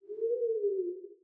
SFX_Mavka_Voice_04.wav